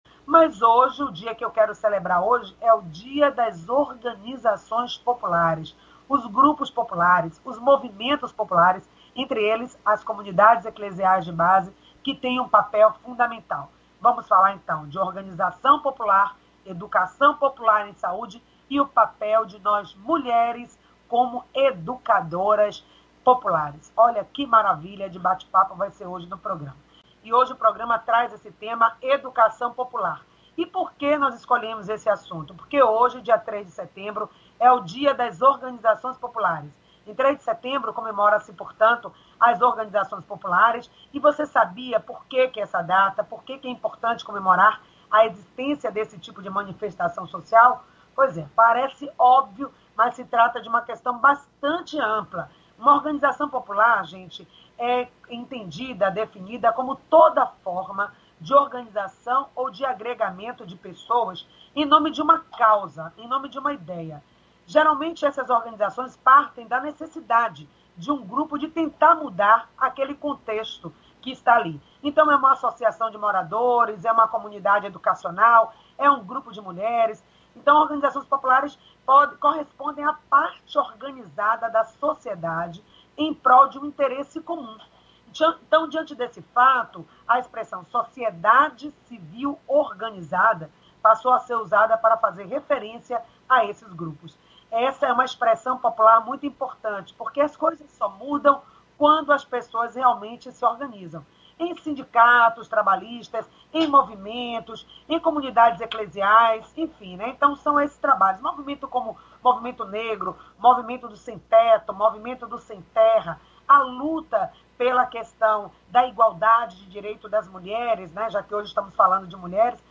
O programa Excelsior Saúde, acontece das 9h às 10h, ao vivo com transmissão pela Rádio Excelsior AM 840.